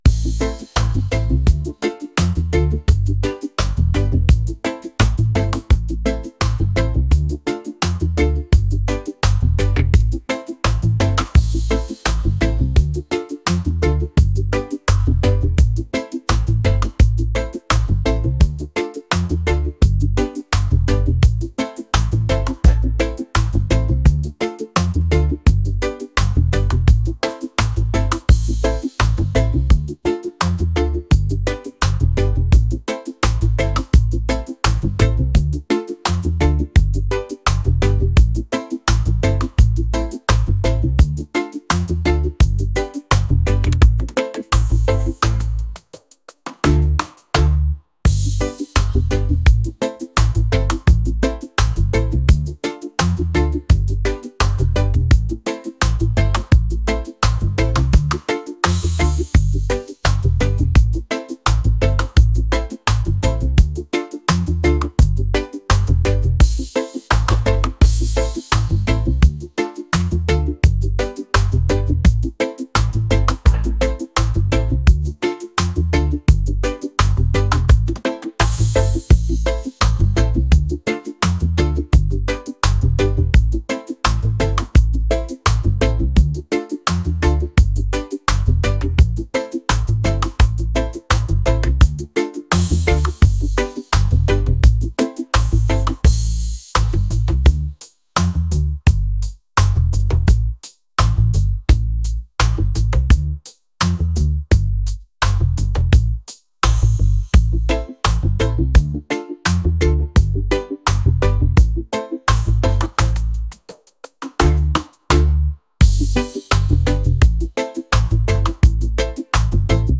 reggae | lofi & chill beats | lounge